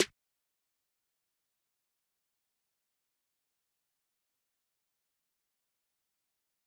Snare 1.wav